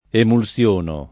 emulsiono [ emul SL1 no ]